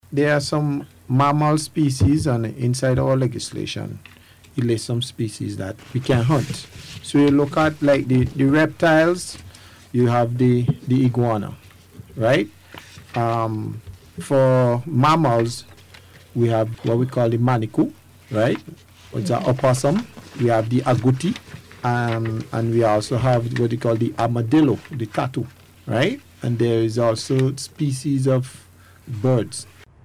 during the Police on the Beat Programme on Monday night.